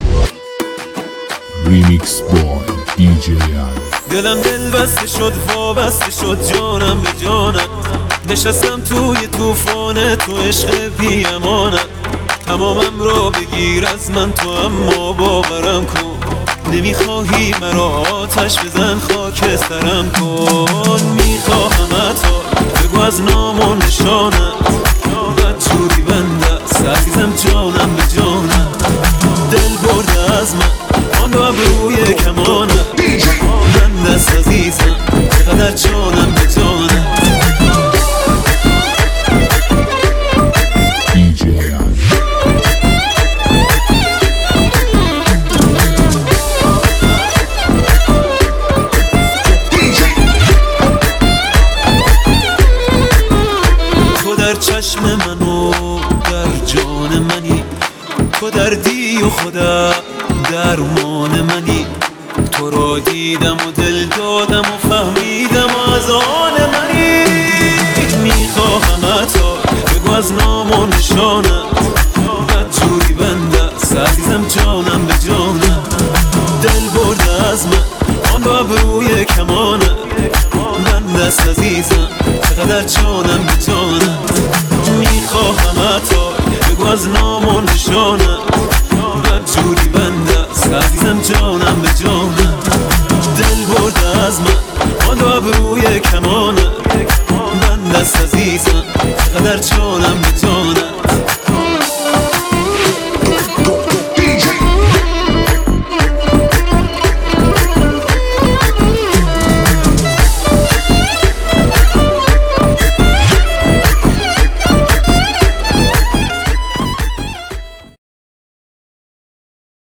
آهنگ شاد و بیس‌دار
بیت‌های مدرن و شاد